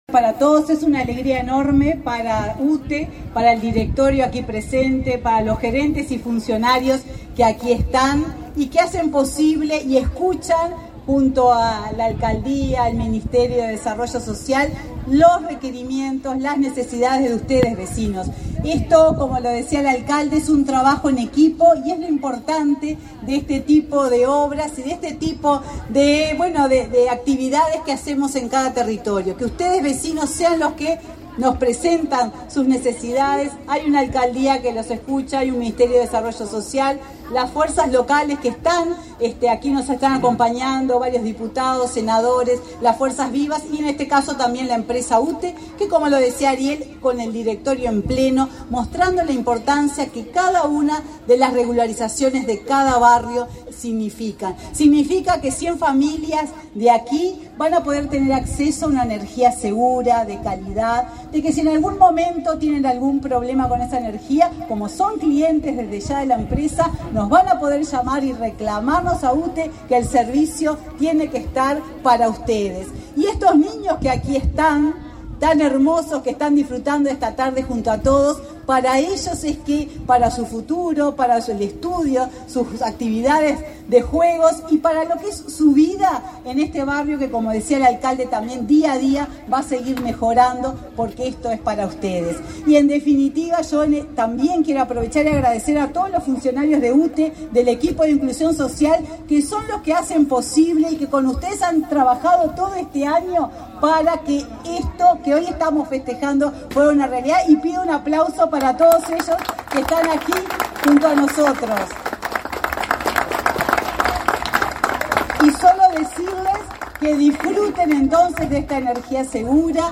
Acto de inauguración de servicios de electricidad rural en Montevideo
Acto de inauguración de servicios de electricidad rural en Montevideo 12/12/2023 Compartir Facebook X Copiar enlace WhatsApp LinkedIn UTE inauguró, este 11 de diciembre, obras de electrificación rural en Montevideo, en el marco del Programa de Inclusión Social. Participaron el ministro de Desarrollo Social, Martín Lema, y la presidenta de la empresa estatal, Silvia Emaldi.